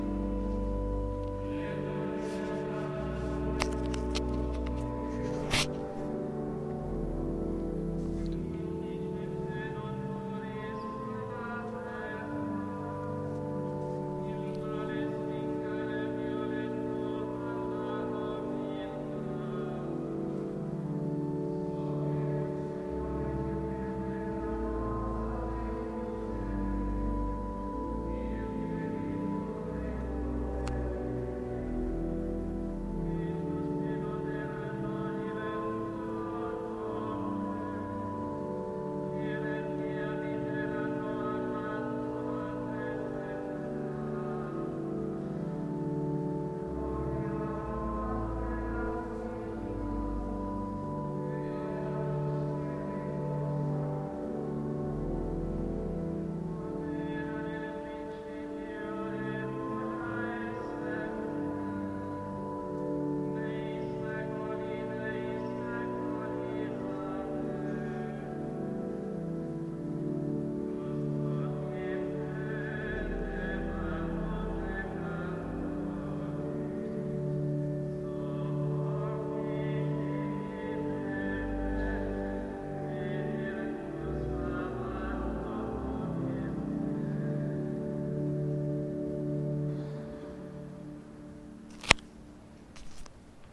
StandingInStPaulsAtMass